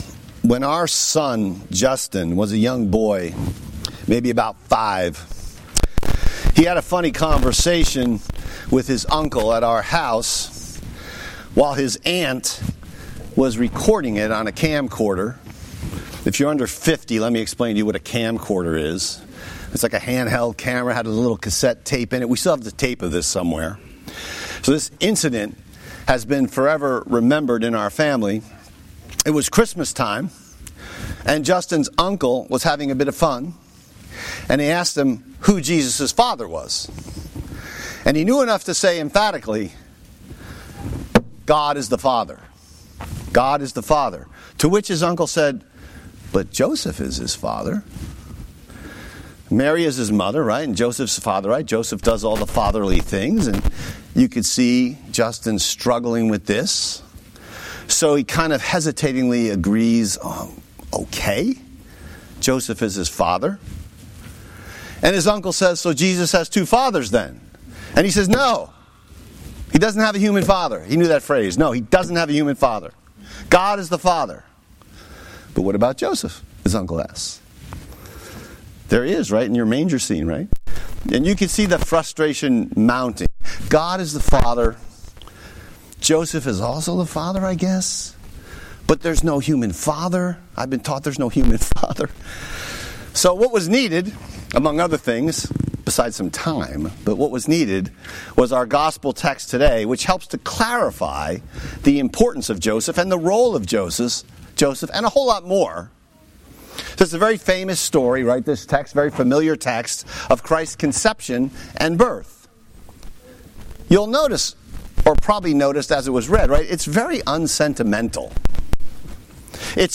Message Text: Matthew 1:18-25